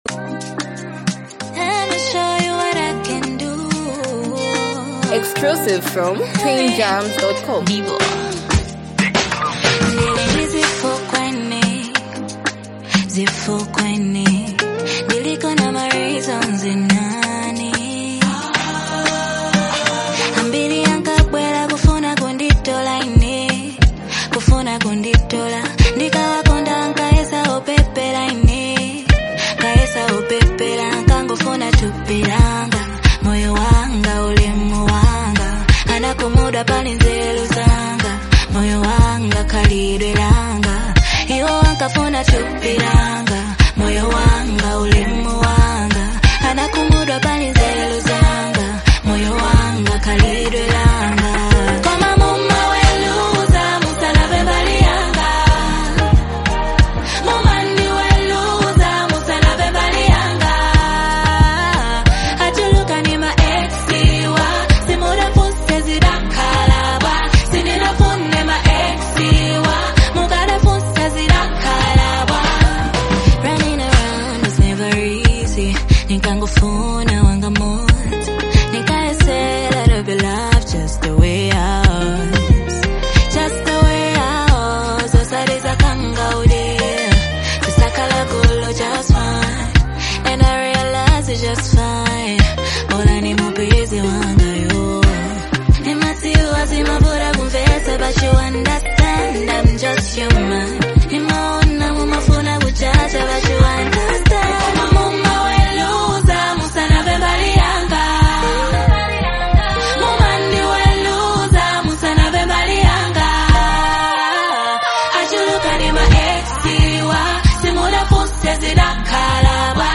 blending Afro‑pop, R&B and uplifting rhythms